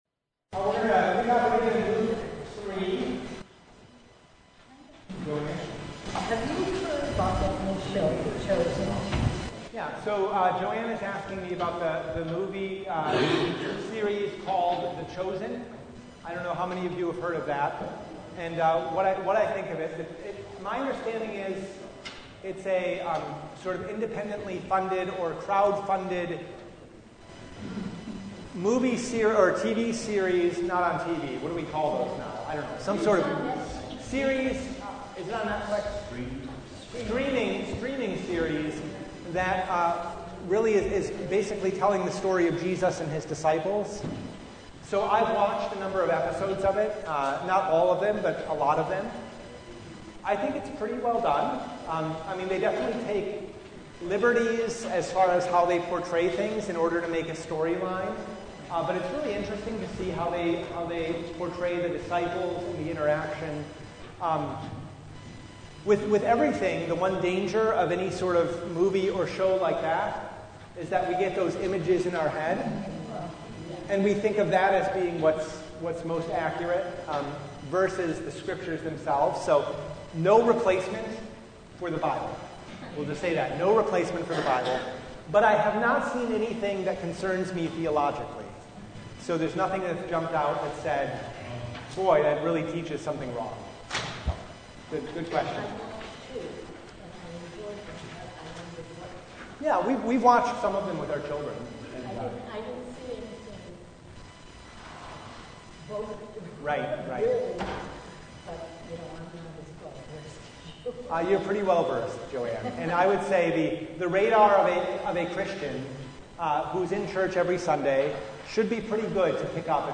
Passage: Luke 3:23-38 Service Type: Bible Study